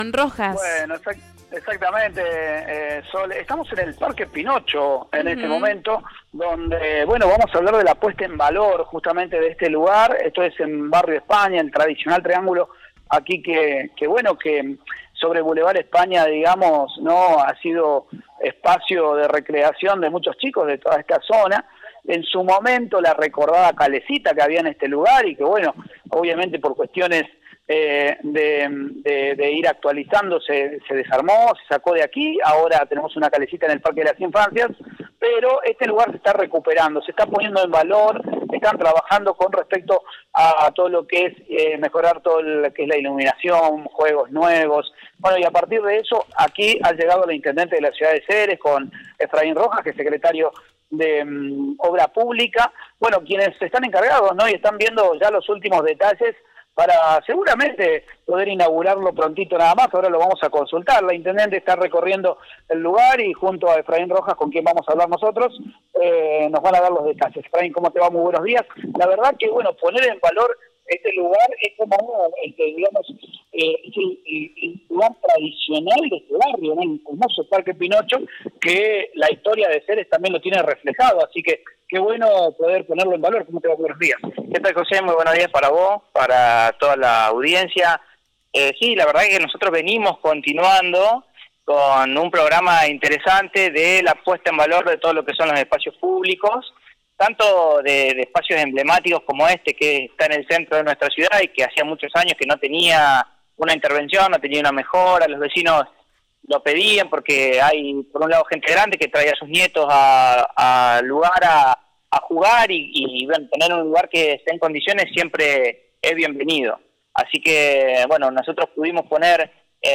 El Secretario habló con Radio EME Ceres para contar sobre el trabajo y cuando se estima inaugurarlo.